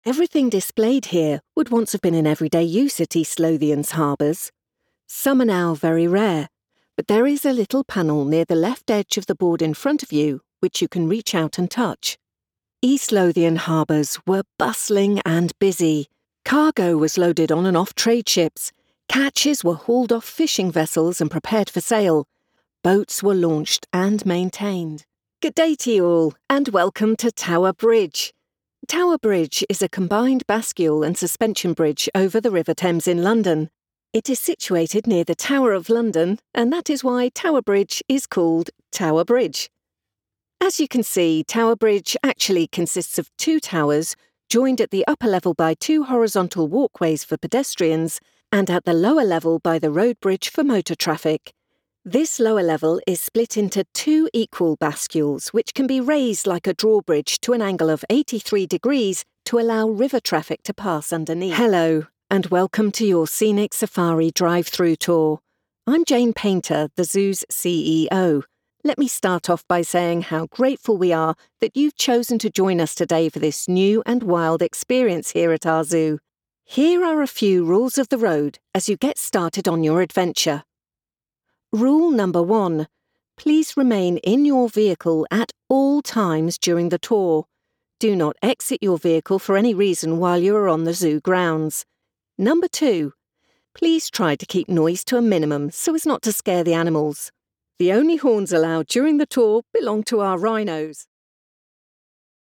Anglais (Britannique)
Mature, Commerciale, Polyvalente, Chaude, Corporative
Guide audio